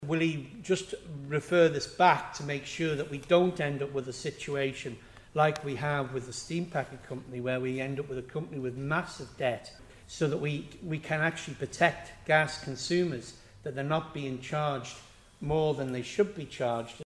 Onchan MHK Peter Karran raised the issue in Tynwald this week - he wanted reassurance that Manx Gas wouldn't be loaded with excessive debt from Brookfield Energy Partners: